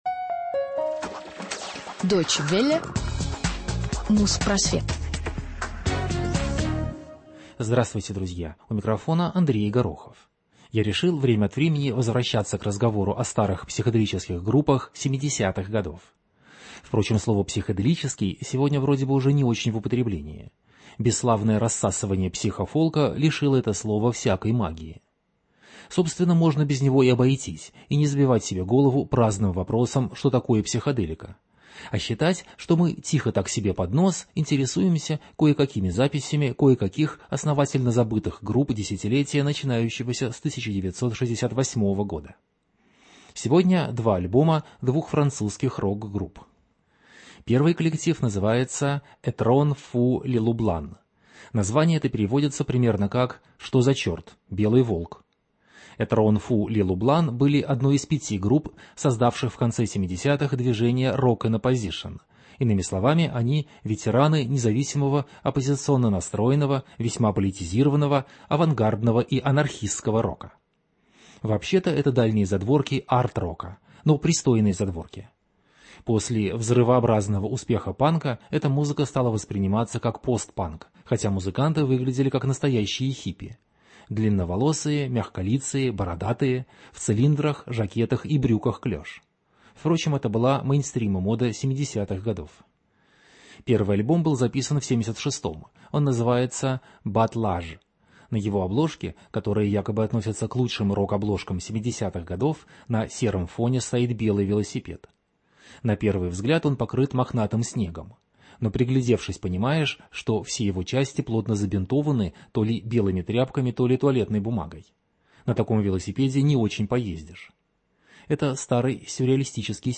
французский андеграундный рок 70х